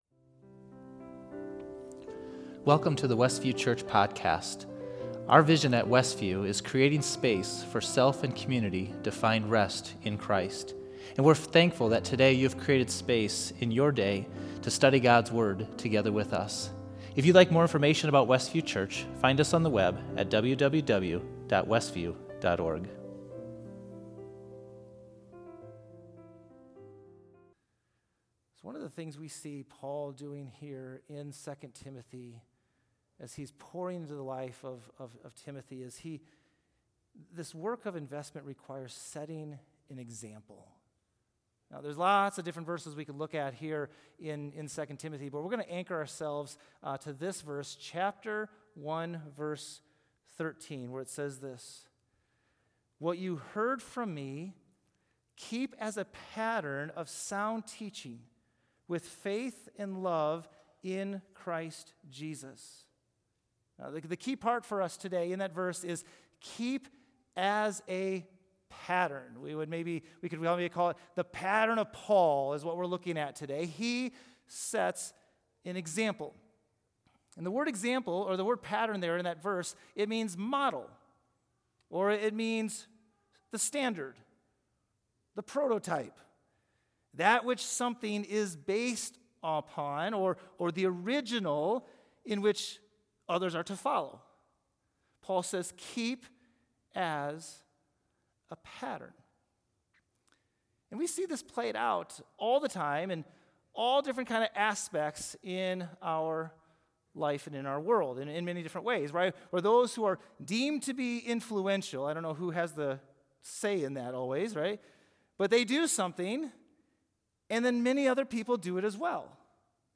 Facebook Livestream